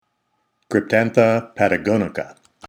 Pronunciation/Pronunciación:
Cryp-tán-tha  pa-ta-gò-ni-ca